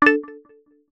zing.ogg